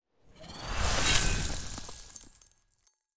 GlacialBomb.wav